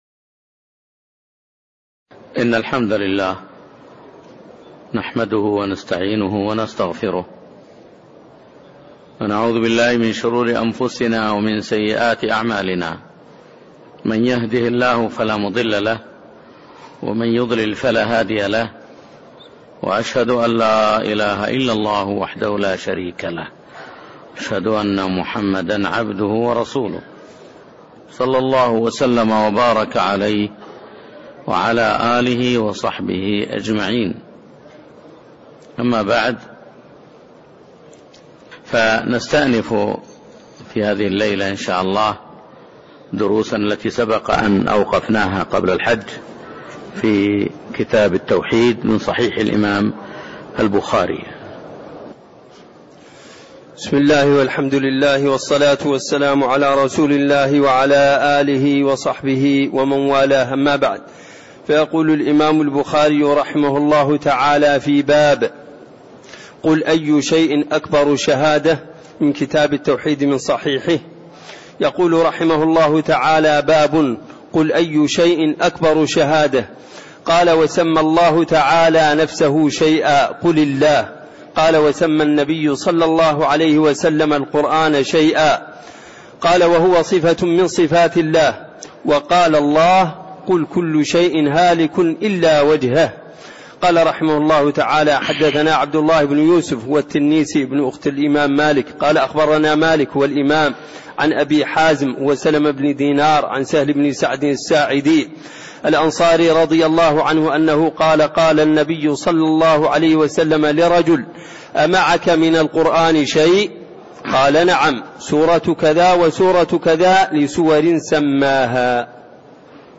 تاريخ النشر ١٨ محرم ١٤٣٤ هـ المكان: المسجد النبوي الشيخ